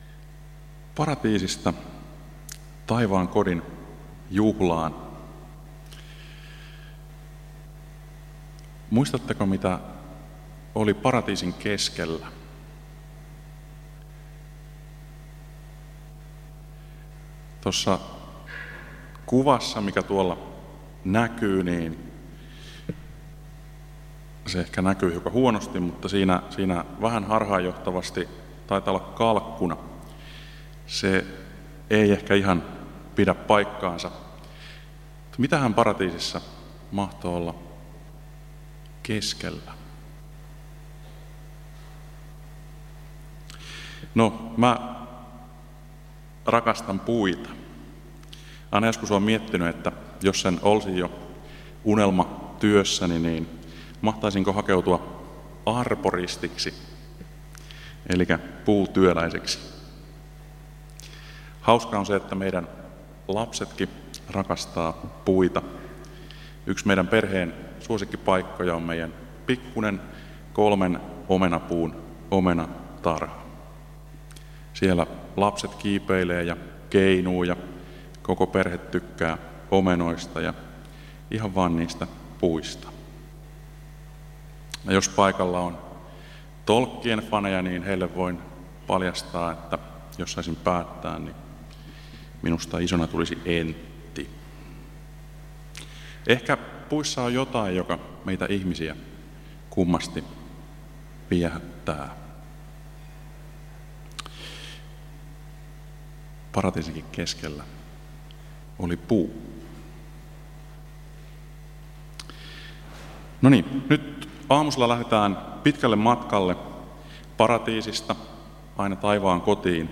Kokoelmat: Tampereen evankeliumijuhlat 2020